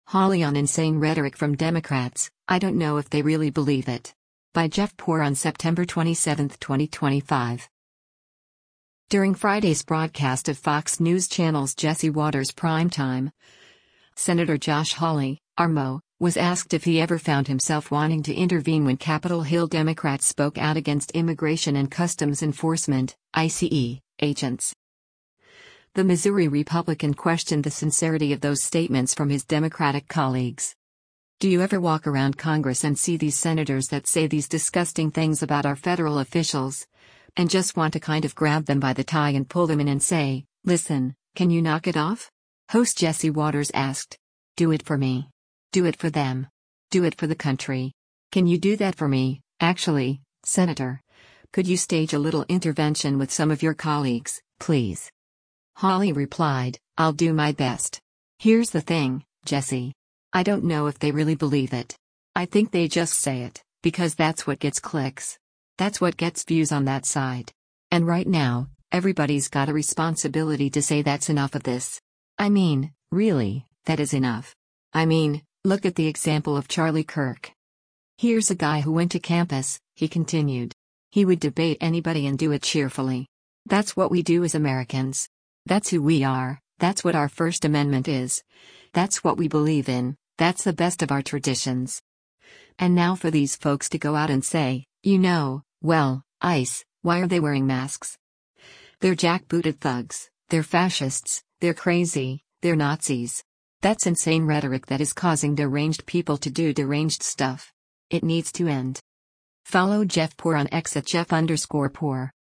During Friday’s broadcast of Fox News Channel’s “Jesse Watters Primetime,” Sen. Josh Hawley (R-MO) was asked if he ever found himself wanting to intervene when Capitol Hill Democrats spoke out against Immigration and Customs Enforcement (ICE) agents.